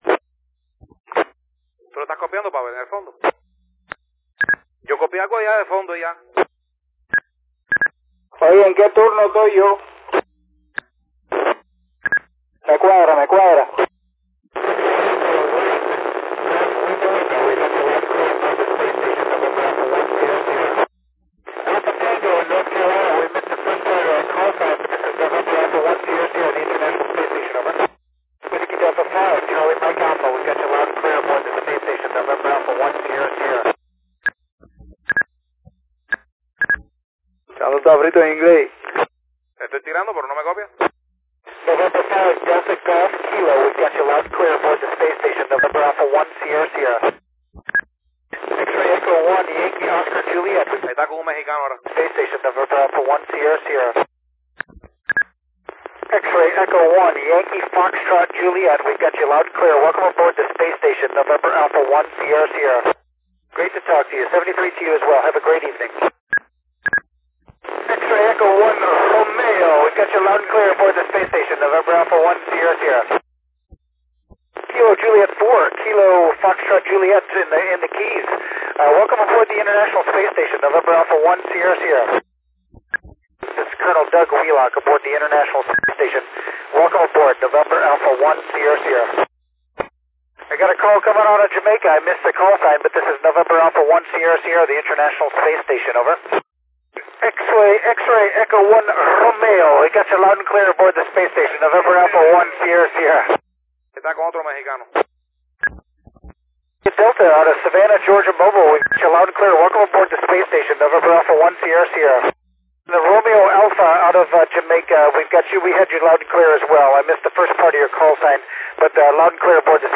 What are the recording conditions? Contact wit the ISS as 20 September 2010, two cuban stations at the end, sorry for not to list the entire QSO list...